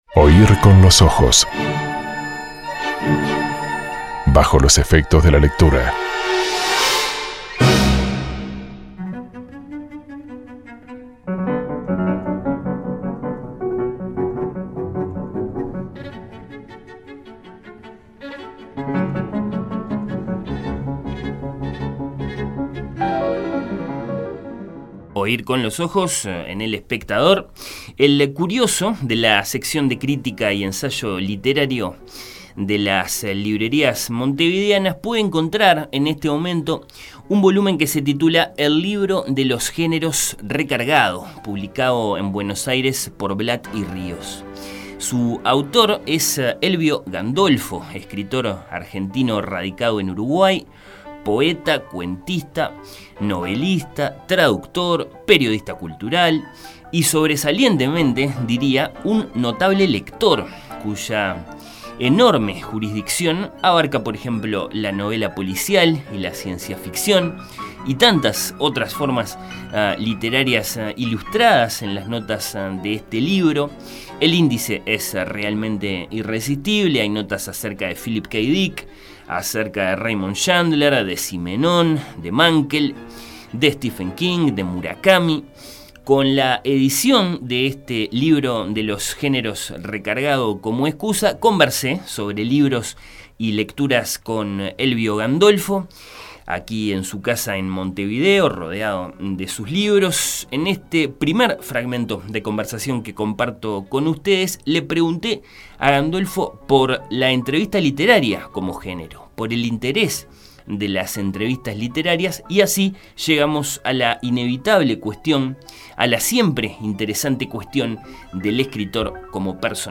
propició el siguiente diálogo